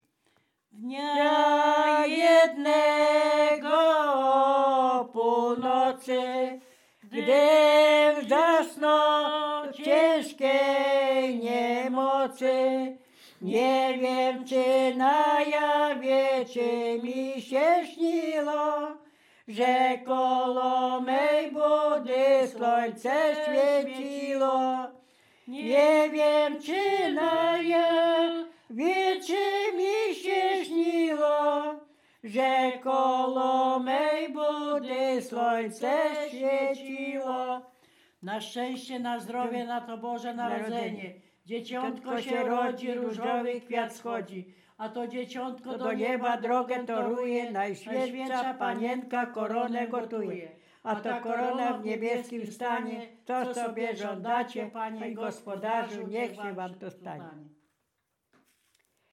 województwo dolnośląskie, powiat lwówecki, gmina Lwówek Śląski, wieś Zbylutów
Kolęda